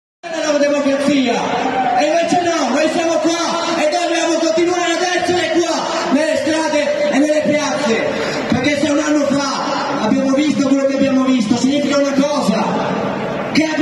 In Italien wurde am 17. Oktober wieder einmal demonstriert, diesmal mit der Botschaft: Kein Krieg, keine NATO und auch für eine Freilassung von Assange...